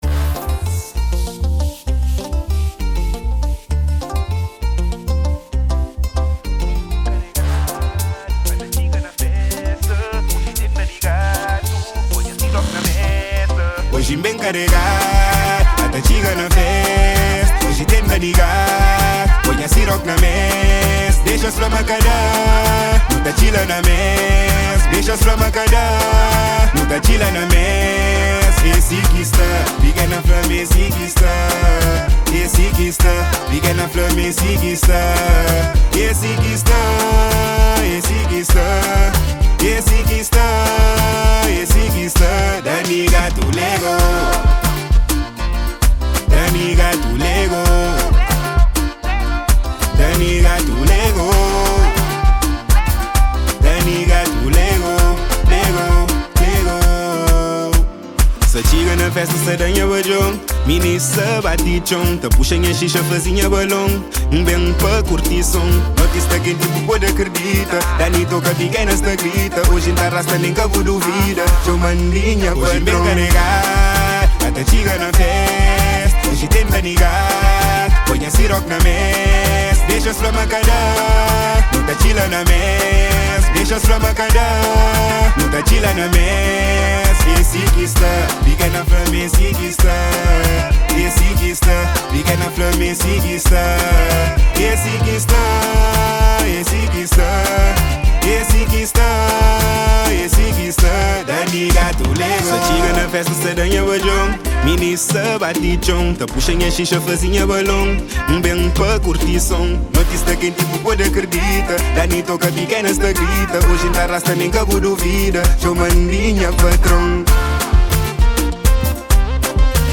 2024-02-16 17:04:07 Gênero: Axé Views